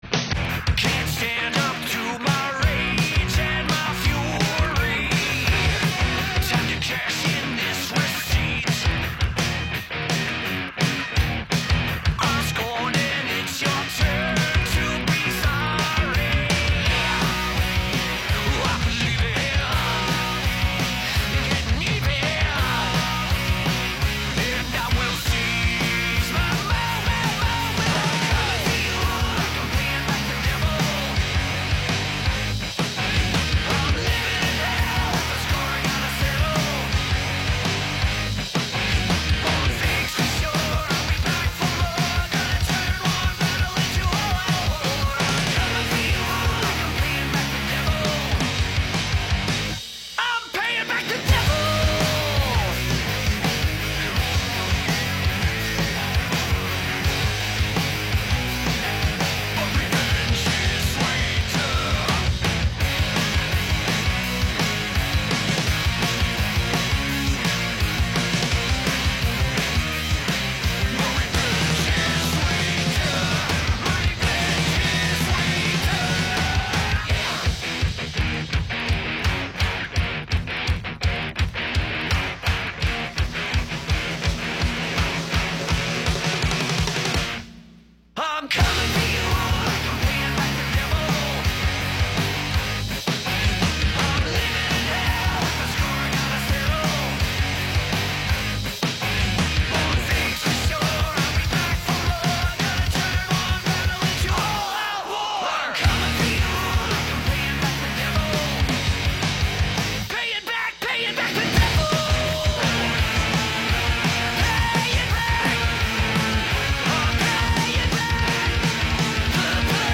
It’s local bands doing cover songs.